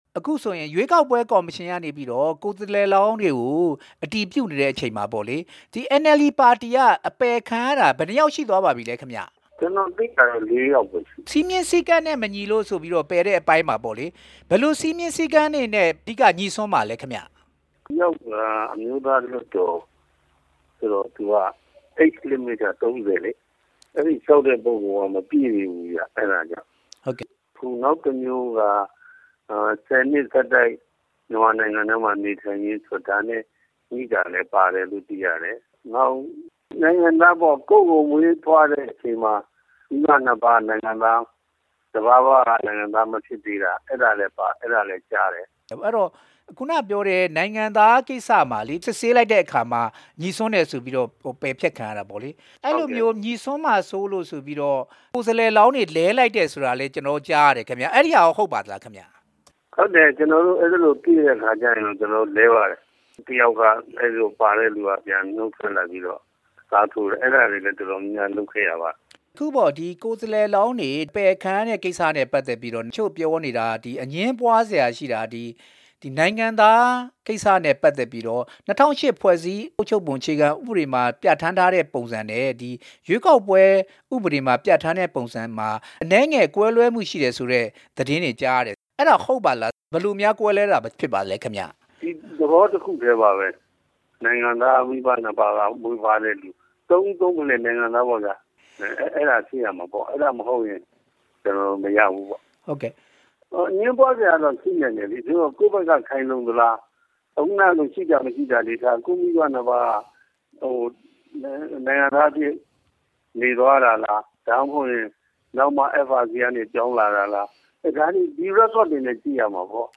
NLD ကိုယ်စားလှယ်လောင်း ၈ ဦး ပယ်ချခံရတဲ့ကိစ္စ ဦးဉာဏ်ဝင်း ကို မေးမြန်းချက်